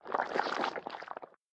snd_slimebossdie.ogg